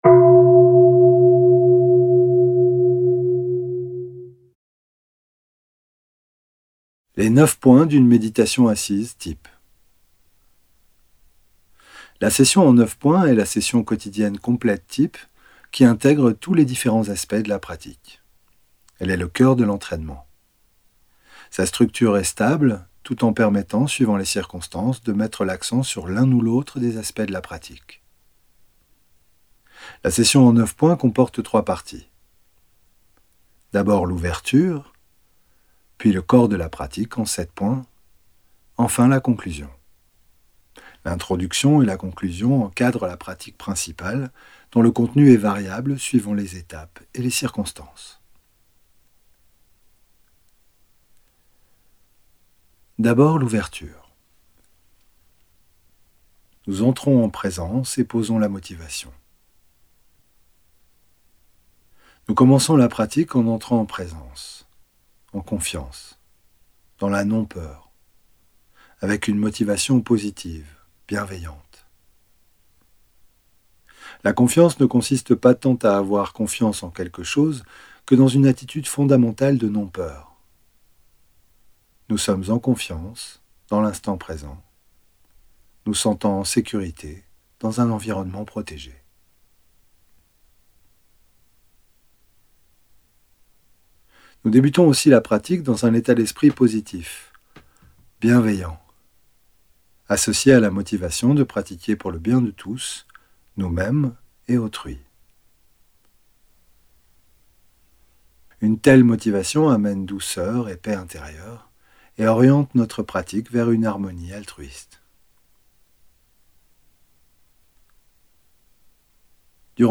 Audio homme